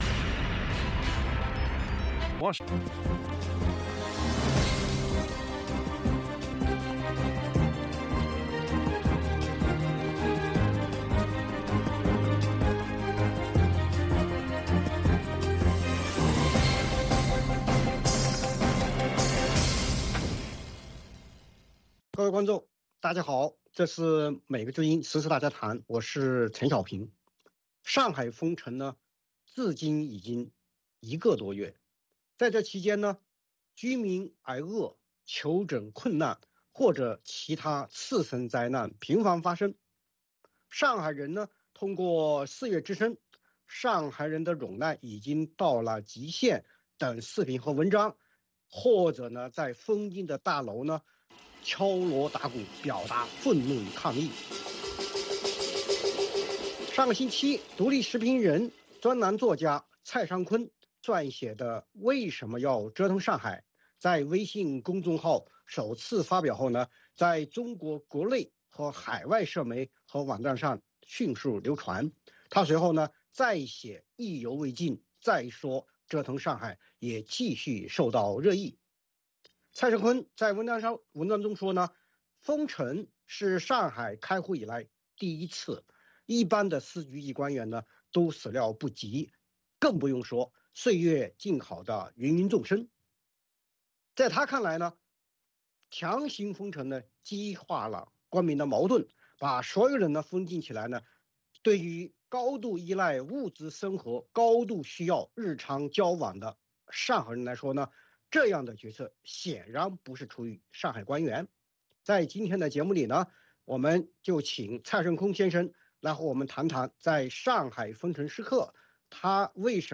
美国之音中文广播于北京时间晚上9点播出《VOA卫视》节目(电视、广播同步播出)。